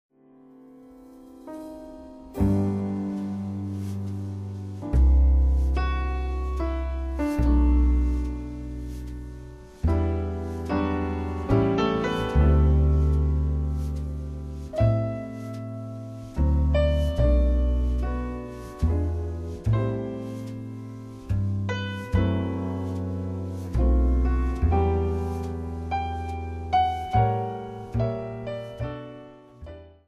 將古典大師的作品改編成爵士三重奏的型式，讓高雅的 古典曲目增添了輕快寫意的風味